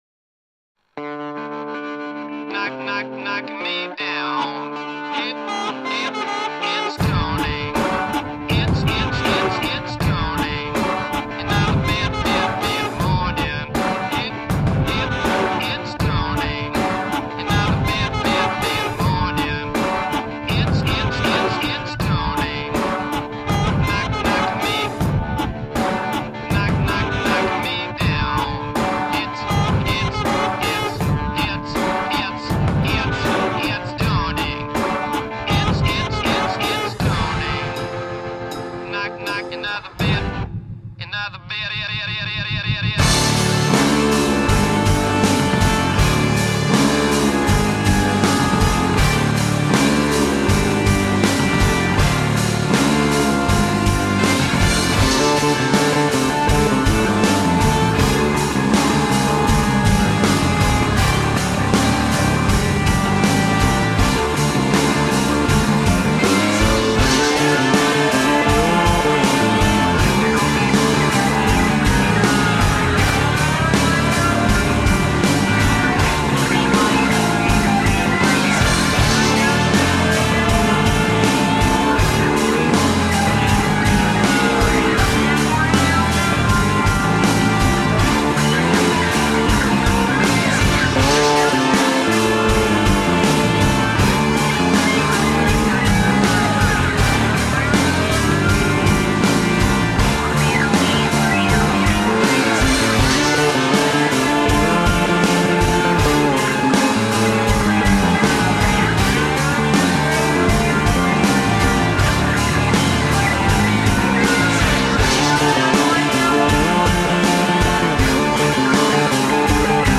Tagged as: Alt Rock, Rock, Classic rock, Instrumental